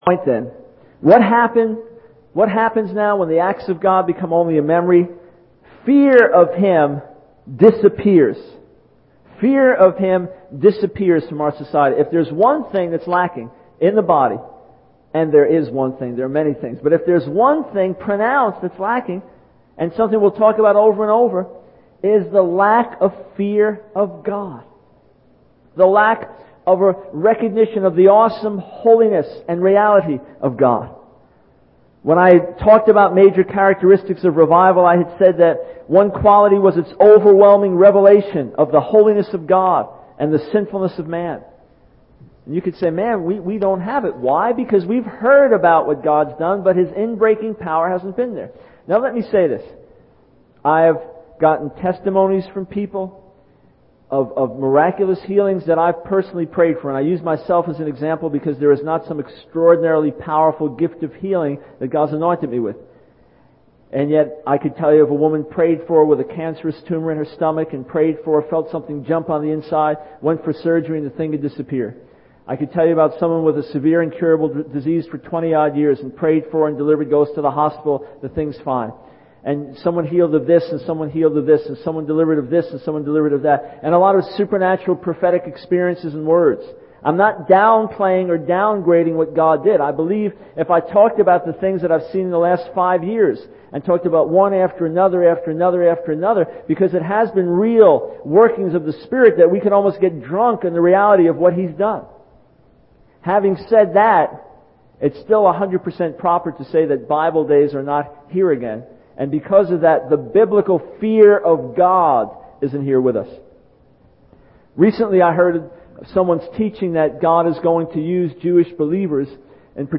In this sermon, the speaker discusses the three steps that lead to the decline of the people of God.